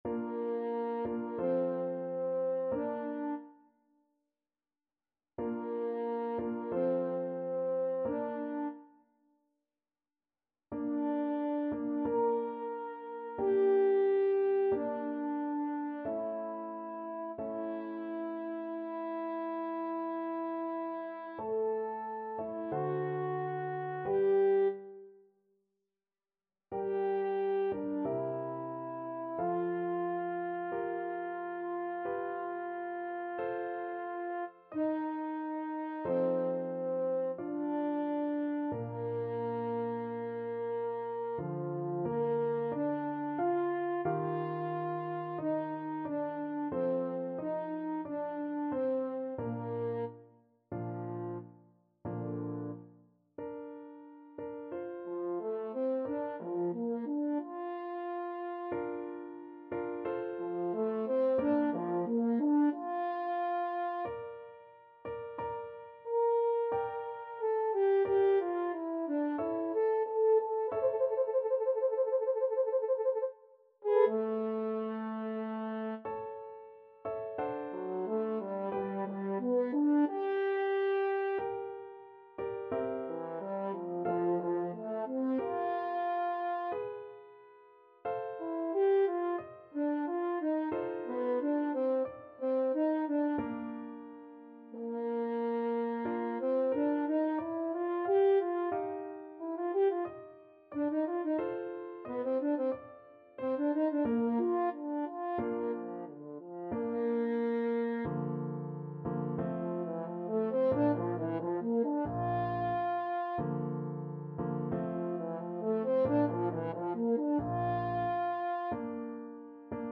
Classical Beethoven, Ludwig van Violin Concerto, Op. 61, Second Movement French Horn version
French Horn
4/4 (View more 4/4 Music)
Bb major (Sounding Pitch) F major (French Horn in F) (View more Bb major Music for French Horn )
Larghetto =c.45
Classical (View more Classical French Horn Music)